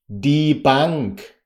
ดี บังค์